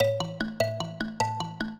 mbira
minuet3-2.wav